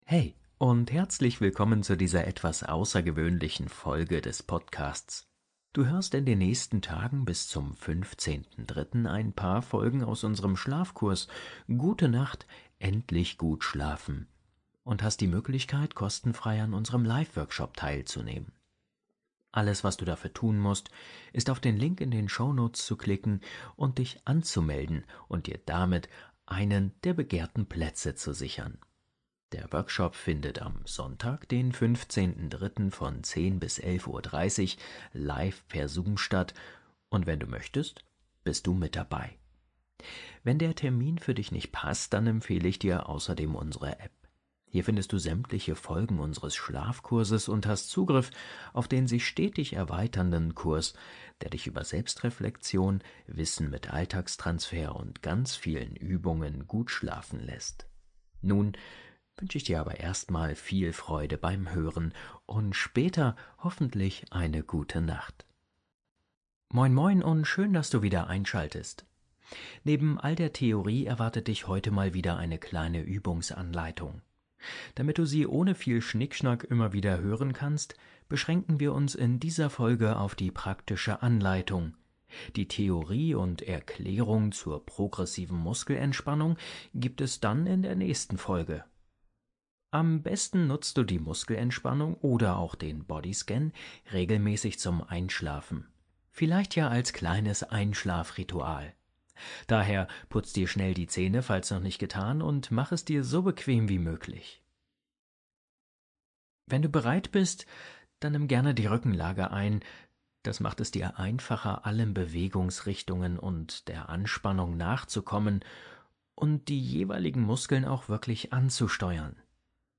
In dieser Praxisfolge des Entspannungshelden Schlafkurses lernst du eine einfache Anleitung zur Progressiven Muskelentspannung (PMR). Die Übung hilft, Muskelspannung zu lösen, den Körper zu beruhigen und leichter einzuschlafen – ideal als Entspannungsroutine vor dem Schlafen.